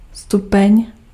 Ääntäminen
US : IPA : [dɪˈɡri] UK : IPA : [dɪˈɡɹiː]